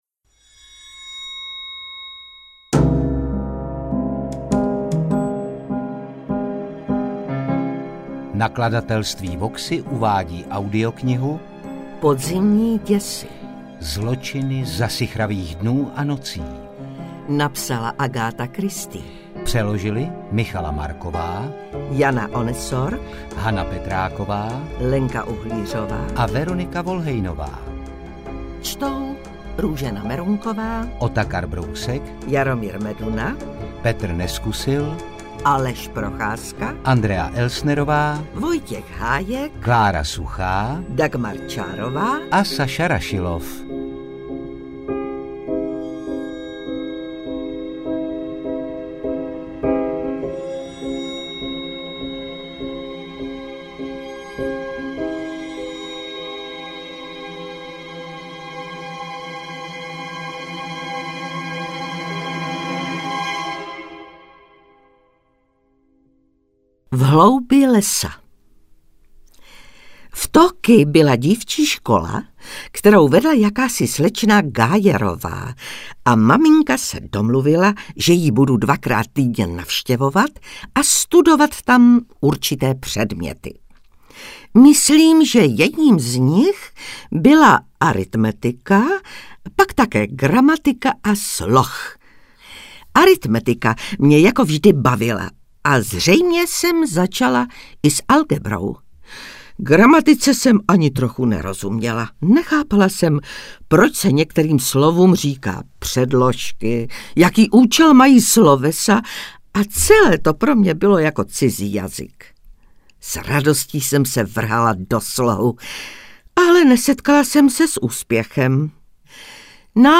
Interpreti:  Otakar Brousek ml., Dagmar Čárová, Jaromír Meduna, Růžena Merunková, Saša Rašilov, kol.